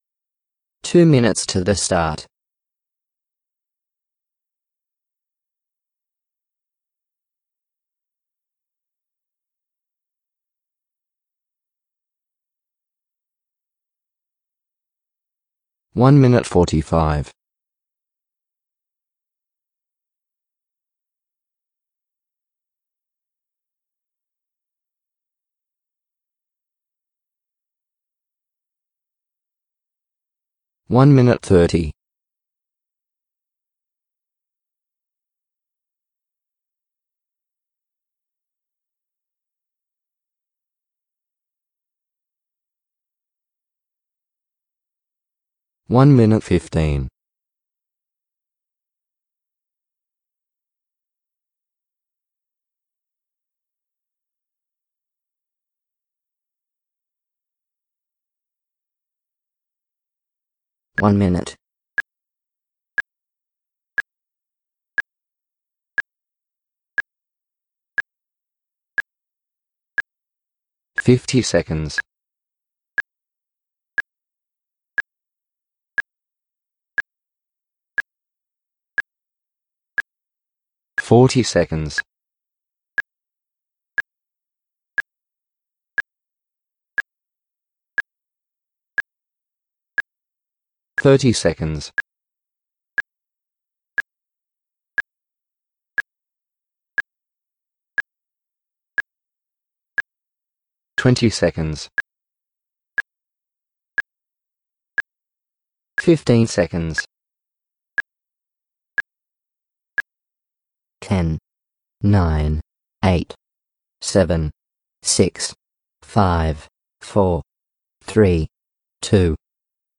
Count Down Timer.  2 minute timer for starting races. Has 30s blank at end of file.  Uses 'Australian' voice.
2 minute count down.mp3